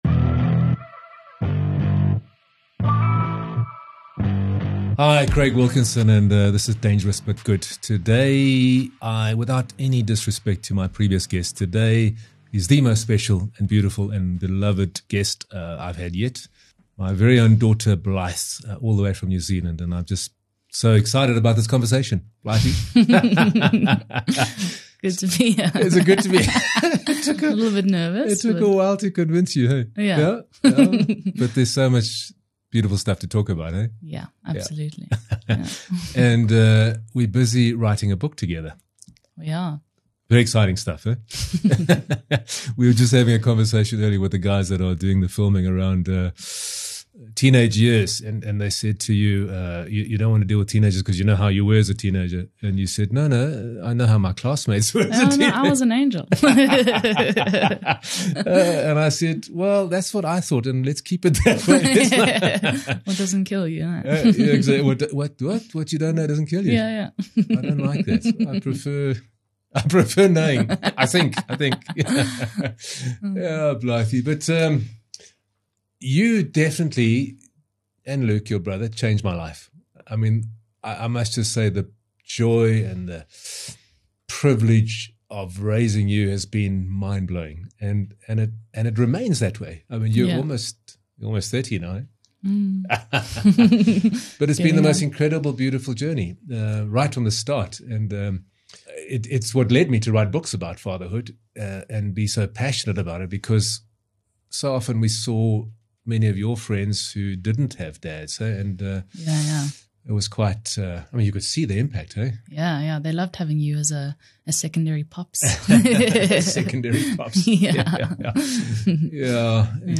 In this unscripted, authentic conversation we unpack the lessons learned, the mistakes made, navigating the pain of divorce and the moments that have meant the most to each of us.